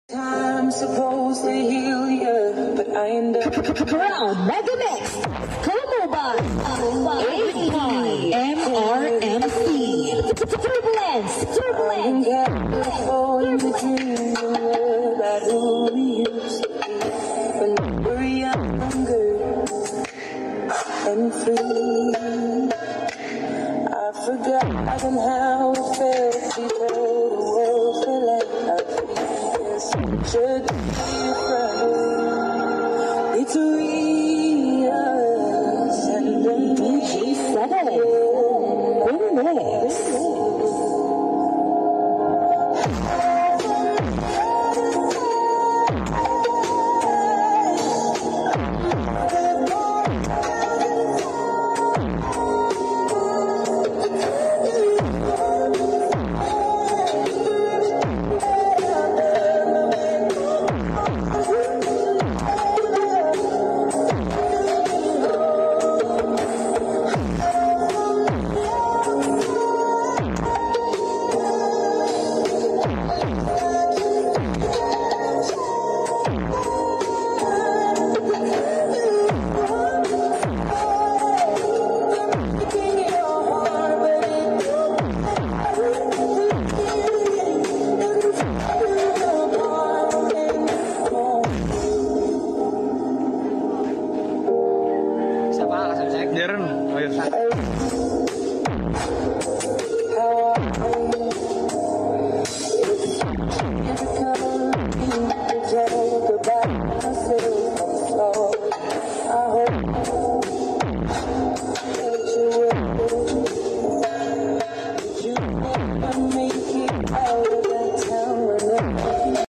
MEGAMIX